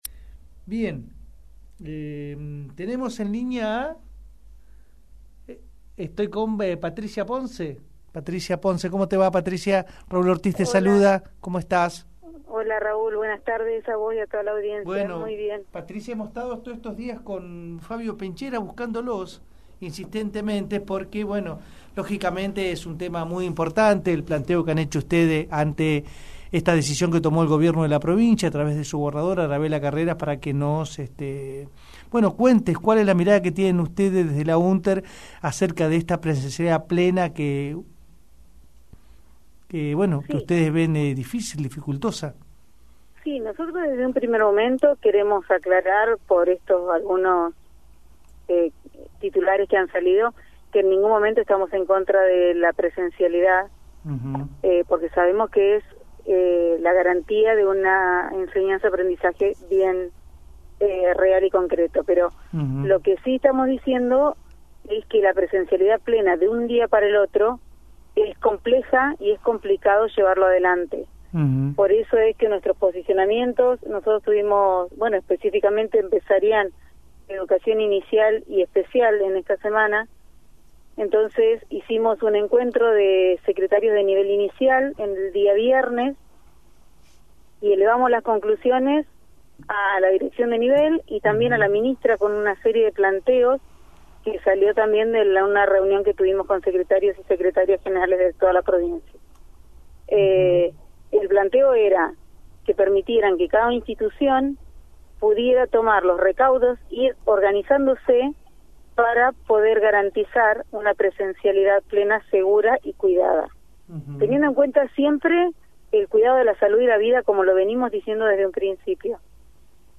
Entrevista radial
en radio “La Carretera”